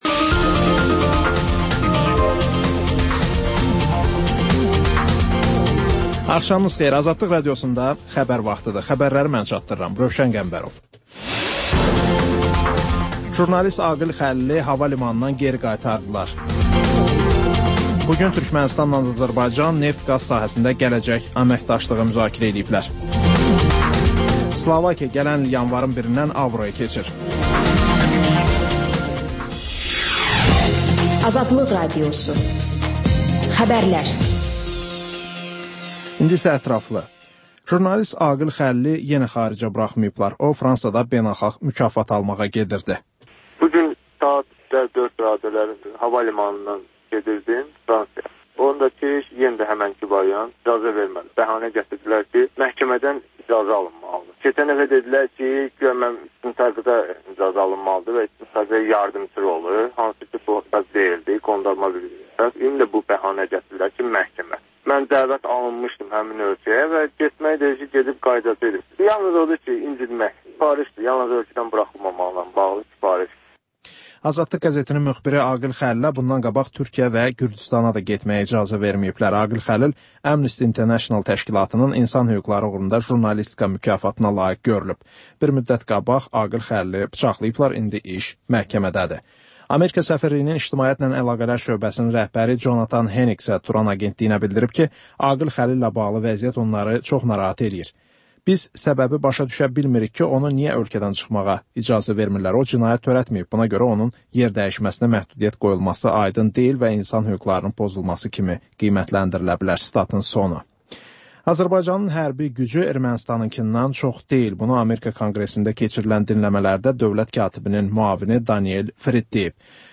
Xəbərlər, REP-TIME: Gənclərin musiqi verilişi